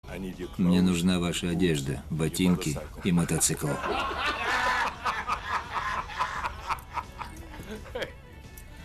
На этой странице собраны iconic soundbites из фильмов «Терминатор» — от холодного голоса T-800 до угроз Скайнета.
В коллекции только самые узнаваемые фразы с чистым звучанием.